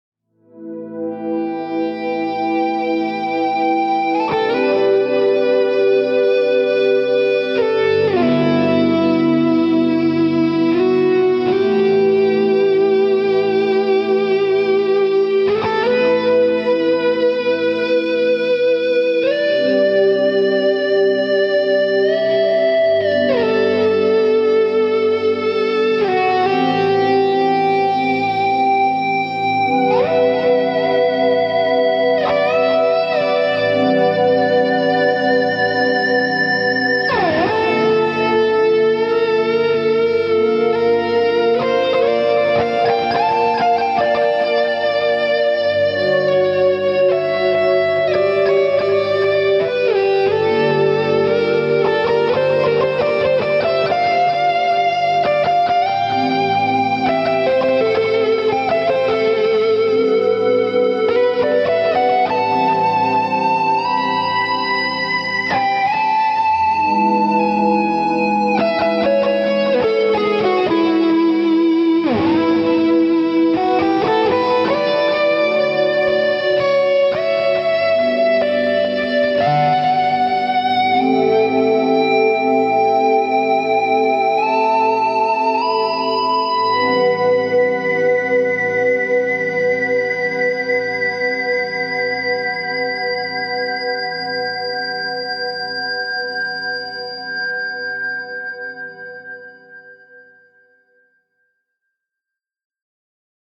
clean Sustainiac sound